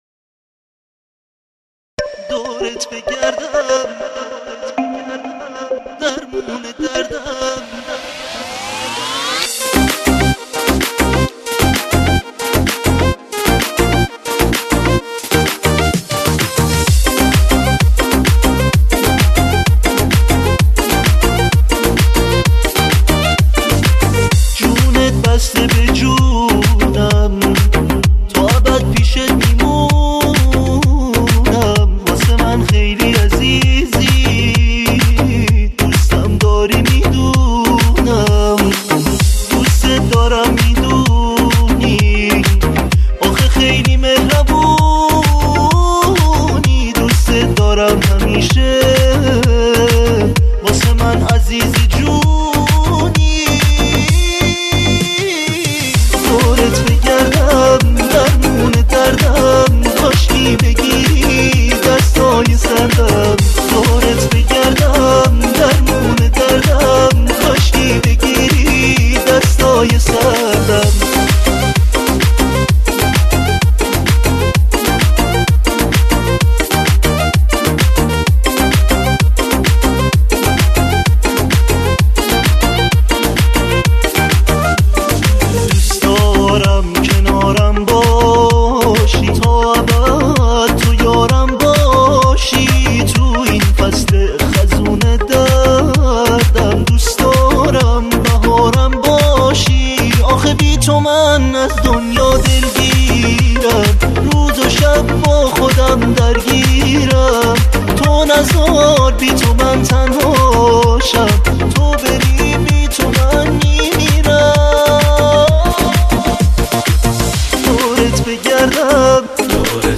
تك آهنگ ایرانی
آهنگ جدید ، شاد و فوق العاده زیبای